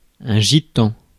Ääntäminen
Synonyymit tsigane tzigane rom Ääntäminen France: IPA: [ʒi.tɑ̃] Haettu sana löytyi näillä lähdekielillä: ranska Käännös 1. mustlane Suku: m .